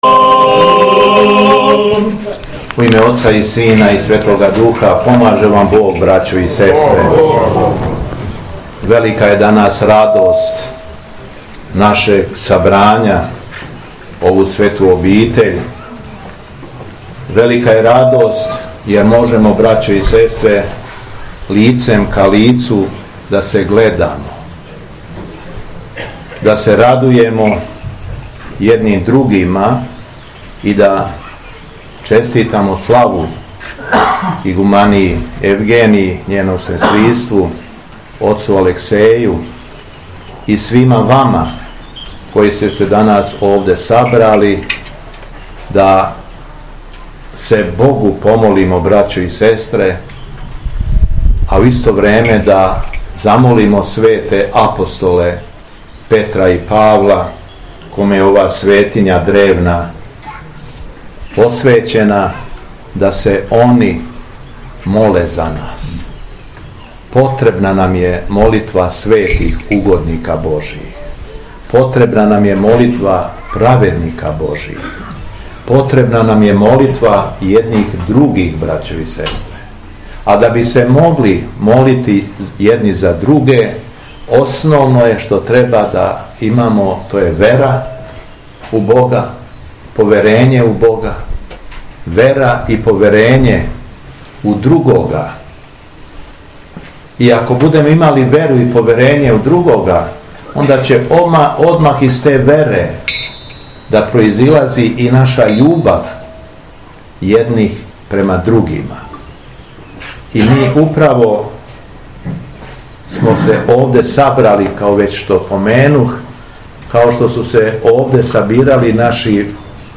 Његово Високопреосвештенство Митрополит шумадијски Господин Јован је у петак, 12. јула 2024. године, на празник Светих апостола Петра и Павла служио Свету архијерејску Литургију у манастиру Ралетинaц, поводом храмовне славе.
Беседа Његовог Високопреосвештенства Митрополита шумадијског г. Јована